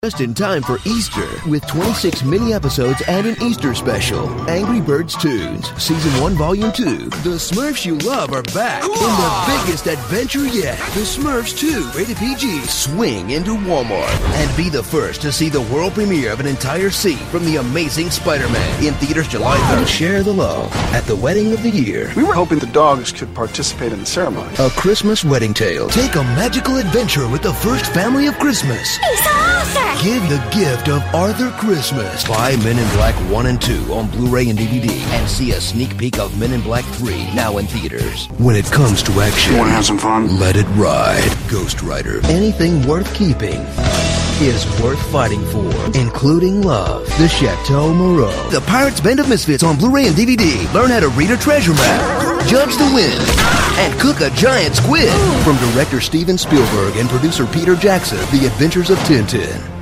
Promo Demo